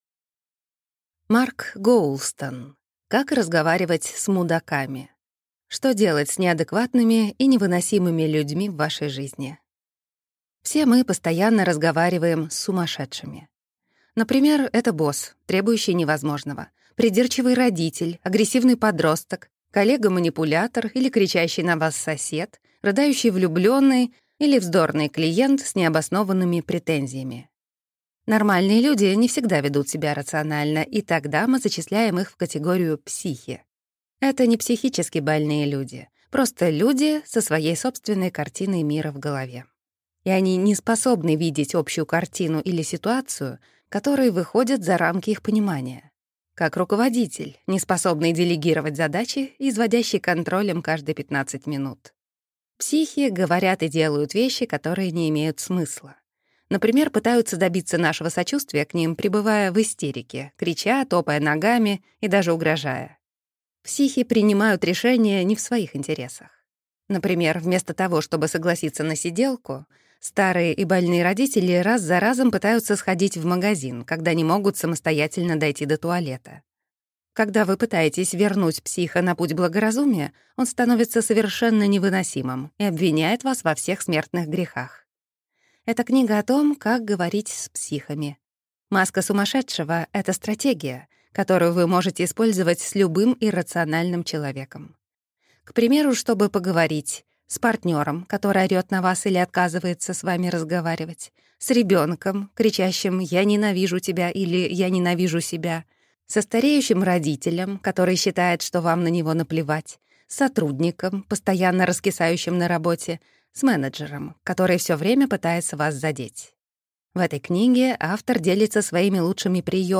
Аудиокнига 5 главных книг по общению в экспертном изложении. Книга 3. Как разговаривать с мудаками.